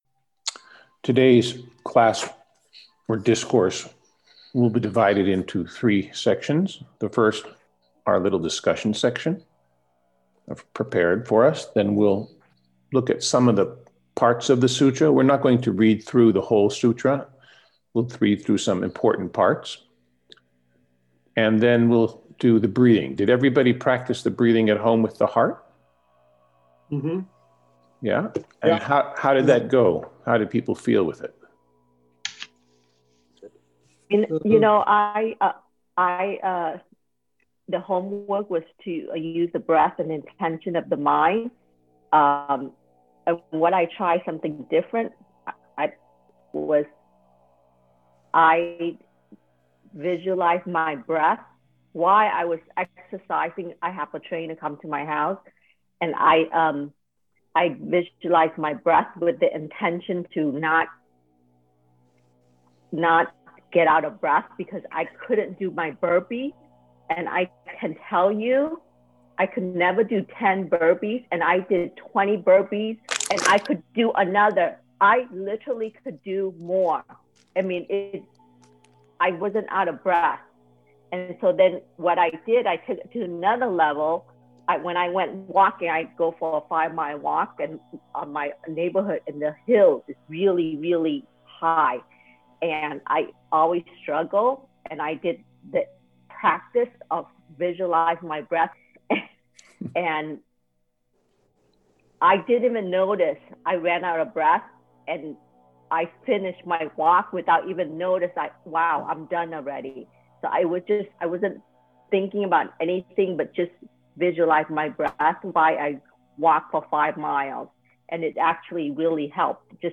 Dear Ones, Sunday 15 November our discourse discusses ‘Why pursue philosophy or spiritual practice?’ What is the purpose and goal and where do we get it from?’ This is a 20 minute excerpt from the live event.